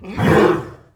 wav / general / combat / creatures / horse / he / attack2.wav